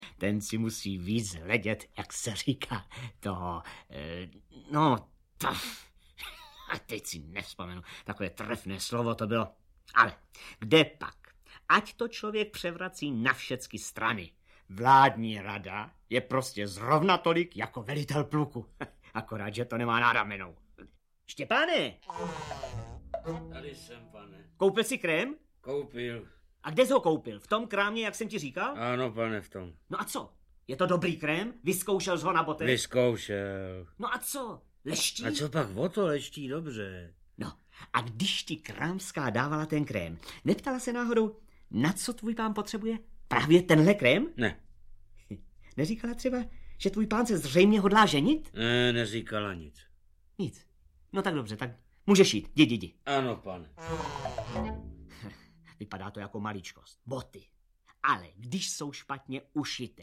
Audiobook
Read: Lubomír Lipský